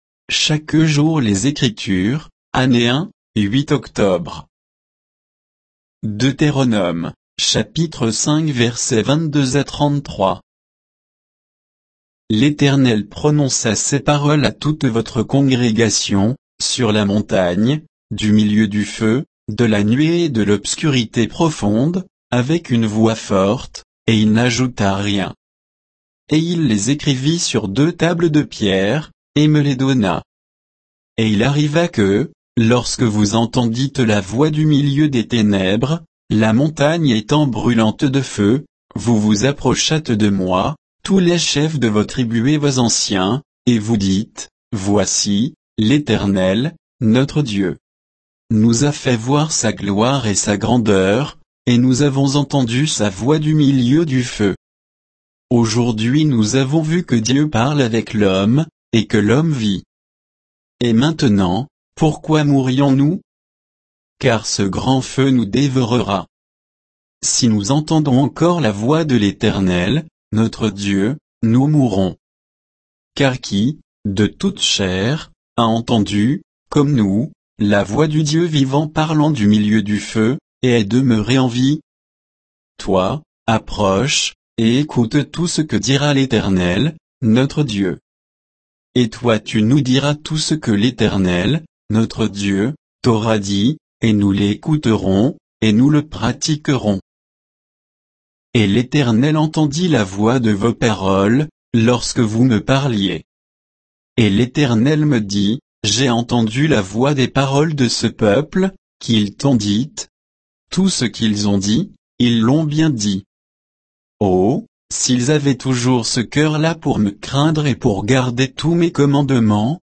Méditation quoditienne de Chaque jour les Écritures sur Deutéronome 5, 22 à 33